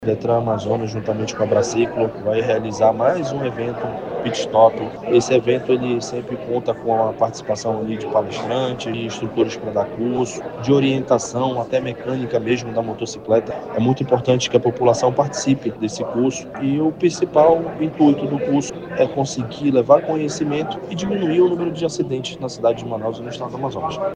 Além de uma palestra educativa, o público poderá interagir com simuladores que mostram os riscos da alta velocidade e os efeitos da embriaguez na direção, explica o Diretor-presidente do Detran-AM, David Fernandes.